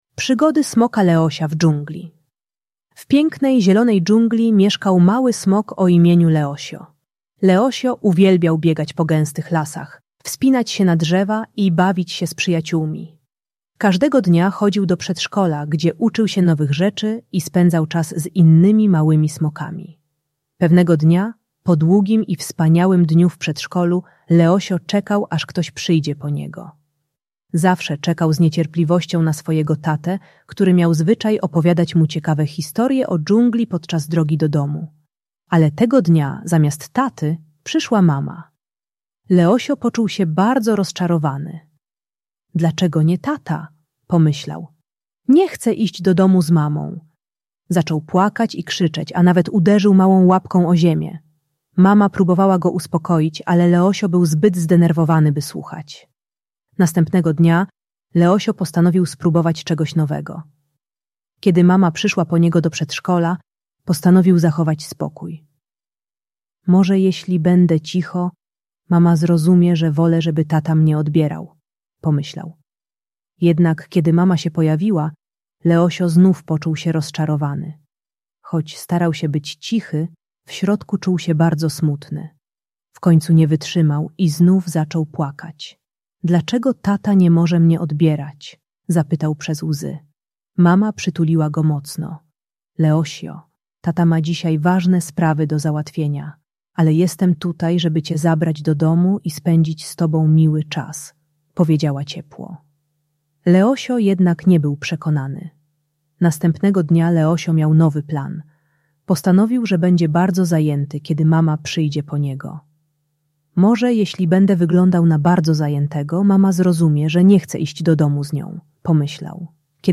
Przygody Smoka Leosia - Przedszkole | Audiobajka